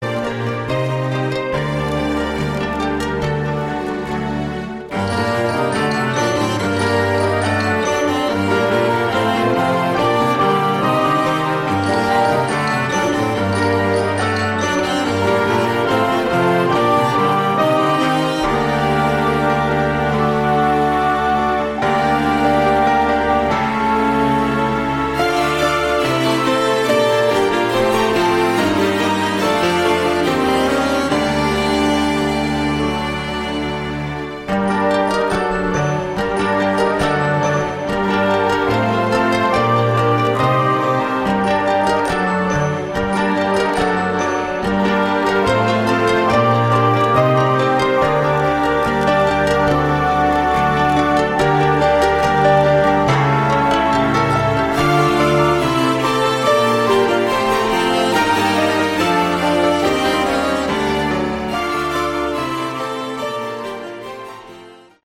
Category: Melodic Rock
vocals, bass
keyboards
guitar
cello
drums
guest harp
guest female voice